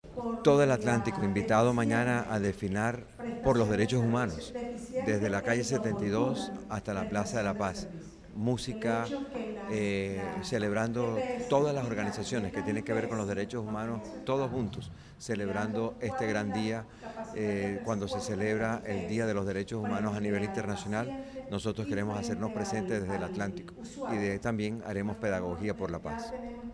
Audio-gobernador-Eduardo-Verano-habla-del-Día-Nacional-de-los-DD.HH_..mp3